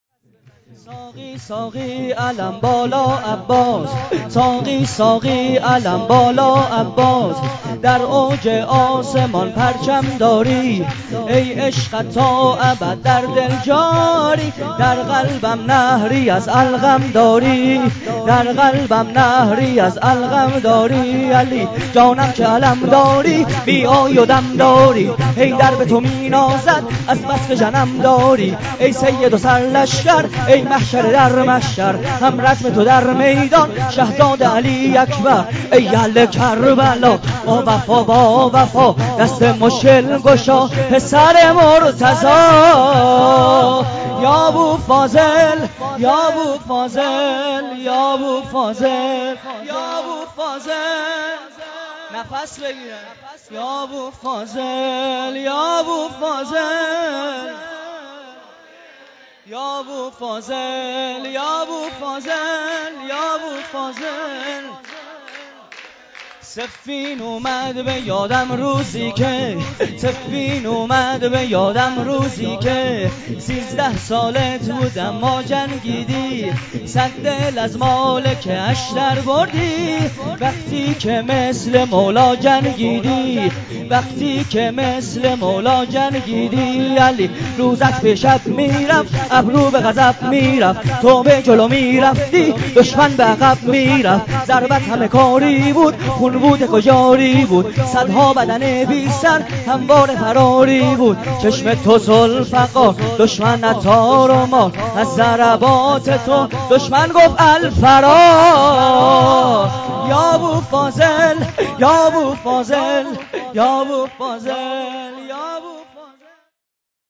ویژه برنامه جشن بزرگ اعیاد شعبانیه و میلاد انوار کربلا1403